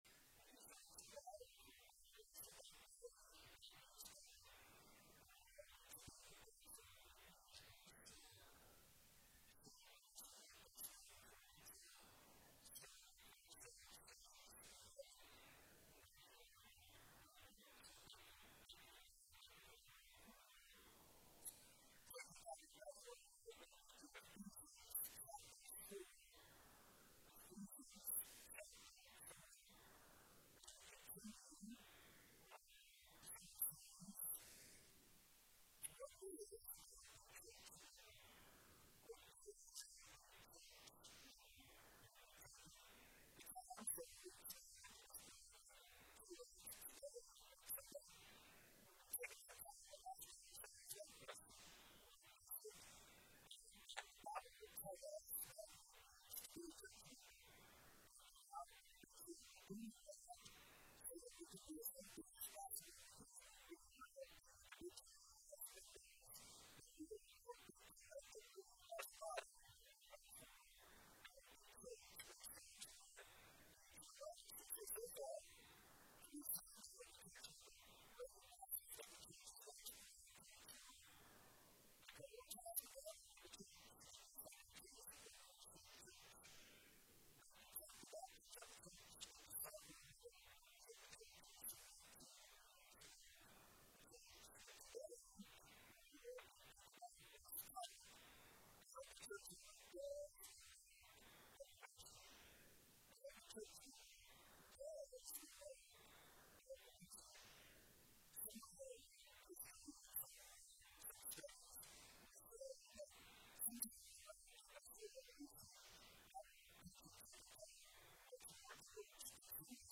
November 9, 2025 Sermon Audio.mp3